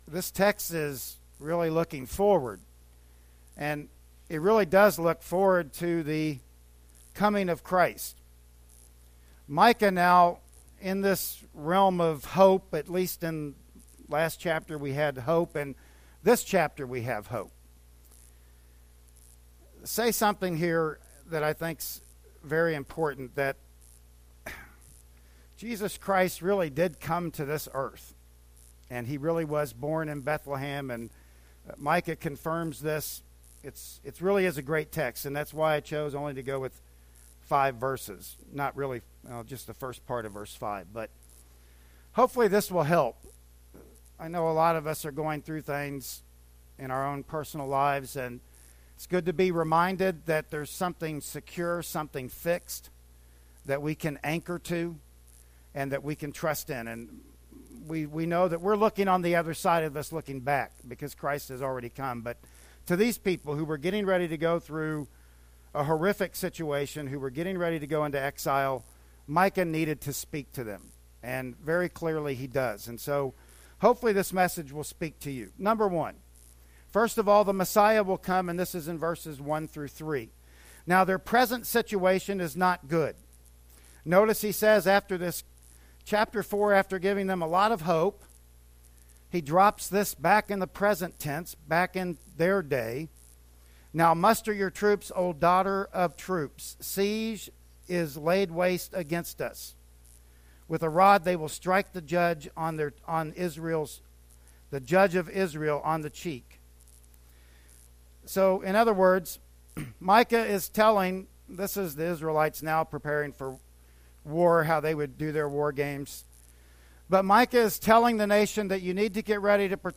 "Micah 5:1-5" Service Type: Sunday Morning Worship Service Bible Text